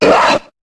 Cri de Draby dans Pokémon HOME.